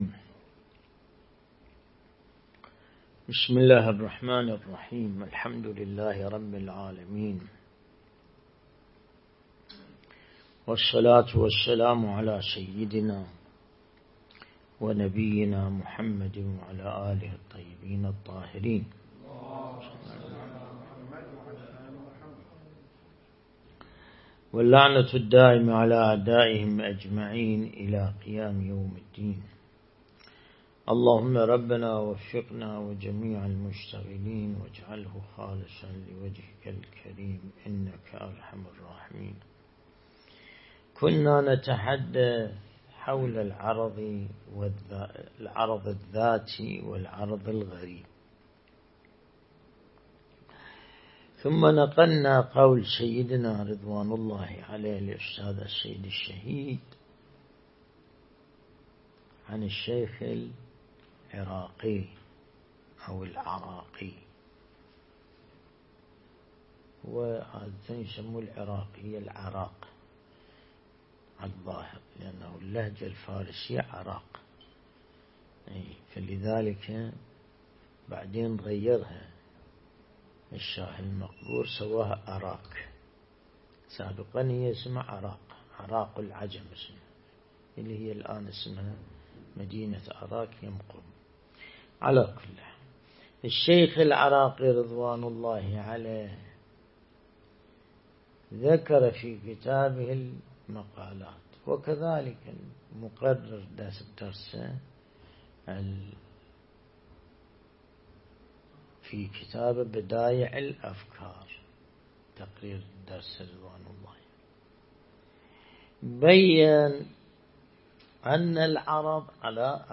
درس البحث الخارج الأصول (47)
درس (47)